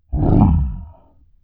dino-roar-03.wav